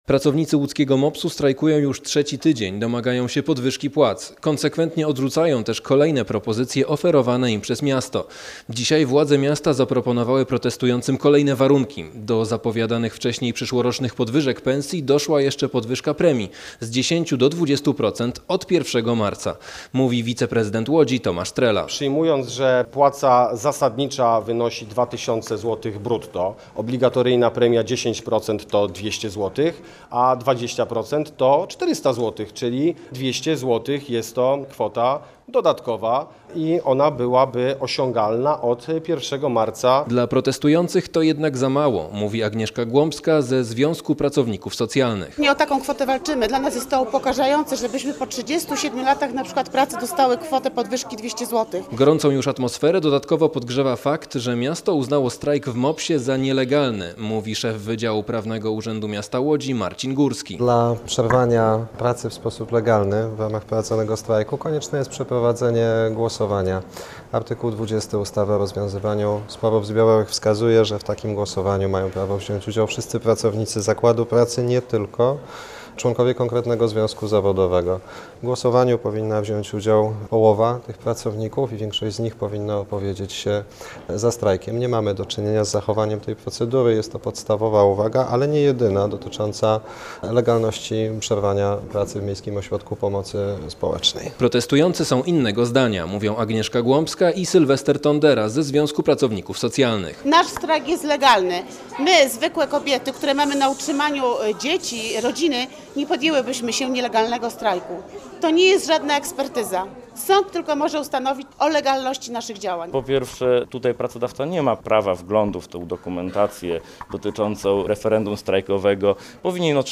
Posłuchaj relacji naszego reportera i dowiedz się więcej: Nazwa Plik Autor Strajk w MOPS nielegalny audio (m4a) audio (oga) ZDJĘCIA, NAGRANIA WIDEO, WIĘCEJ INFORMACJI Z ŁODZI I REGIONU ZNAJDZIESZ W DZIALE “WIADOMOŚCI”.